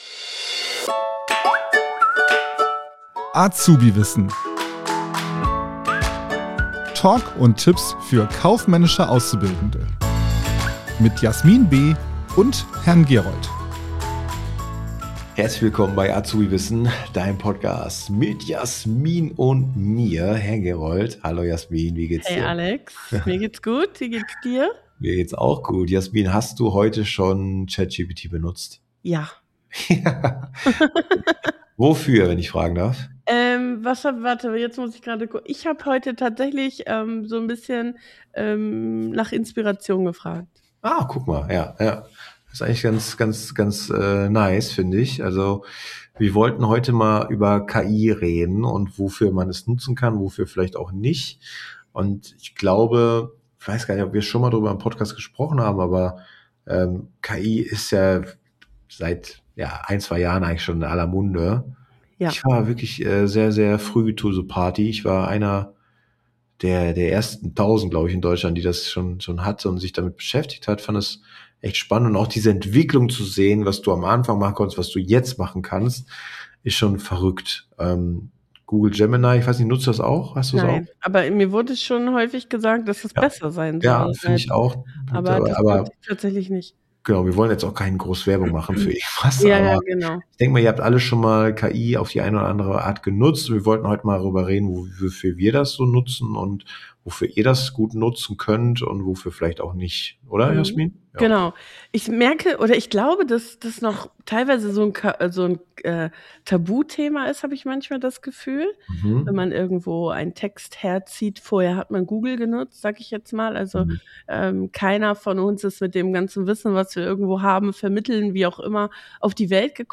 #176 KI in der Ausbildung: Was ist gut, was ist problematisch? ~ AzubiWissen - Talk und Tipps für die kaufmännische Ausbildung Podcast